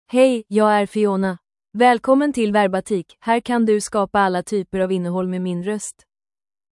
FionaFemale Swedish AI voice
Fiona is a female AI voice for Swedish (Sweden).
Voice sample
Listen to Fiona's female Swedish voice.
Female
Fiona delivers clear pronunciation with authentic Sweden Swedish intonation, making your content sound professionally produced.